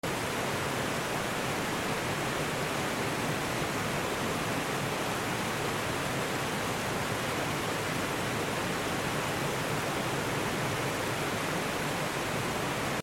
Approaching a magnificent waterfall deep